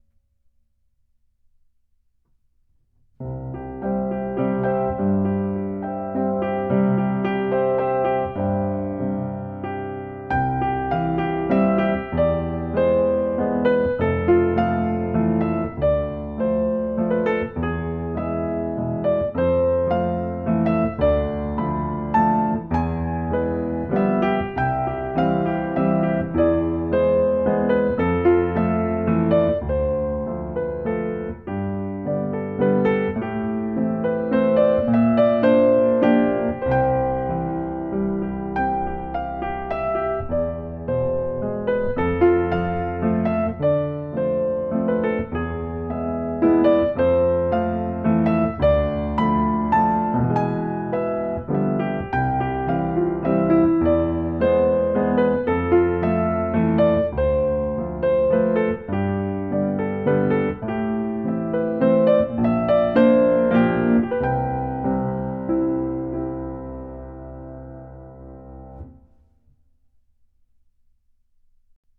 Piano accompaniment
Musical Period 20th Century
Tempo 34
Meter 3/4